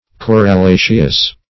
Corallaceous \Cor`al*la"ceous\, a. Like coral, or partaking of its qualities.